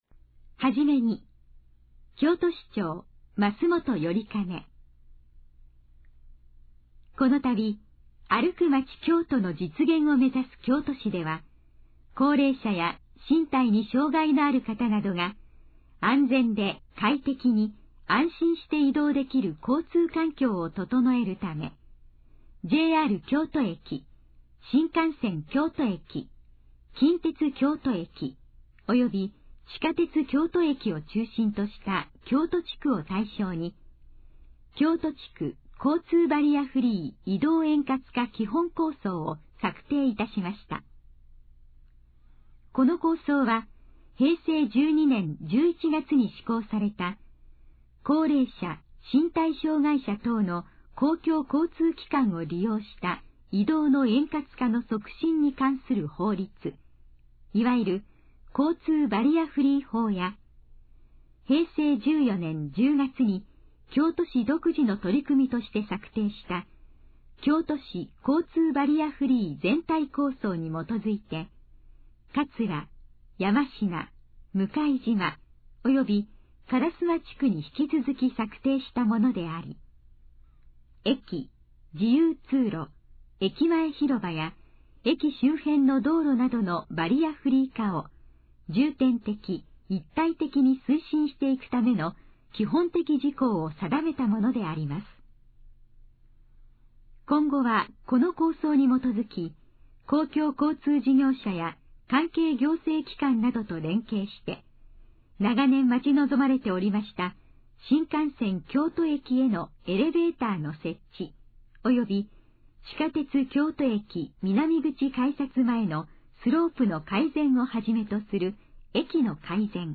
このページの要約を音声で読み上げます。
ナレーション再生 約346KB